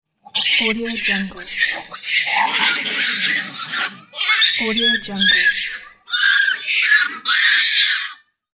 Fighting Monkeys Bouton sonore